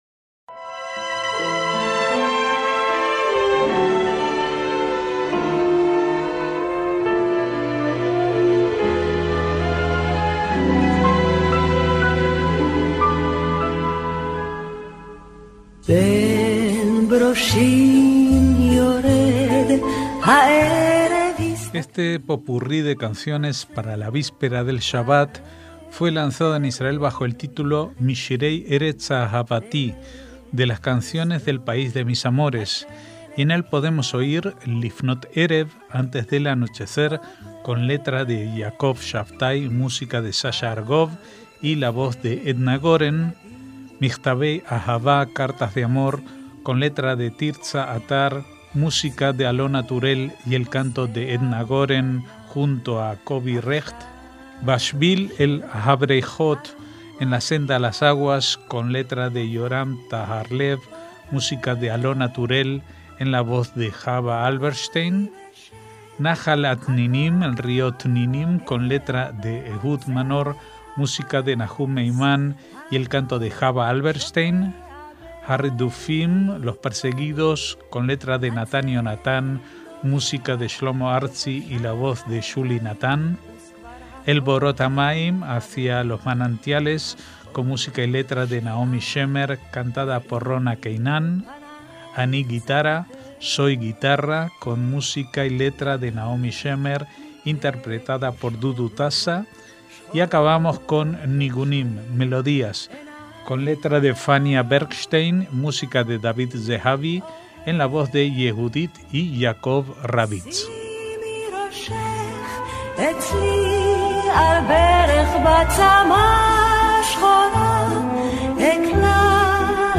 MÚSICA ISRAELÍ
popurrí de canciones para la víspera del shabat